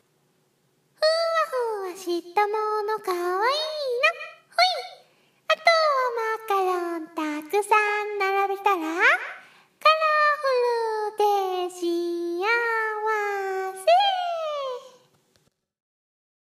ことり声真似